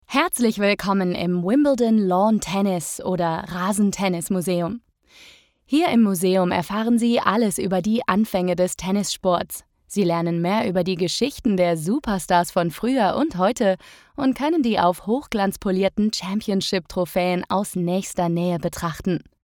Natürlich, Cool, Verspielt, Vielseitig, Freundlich
E-learning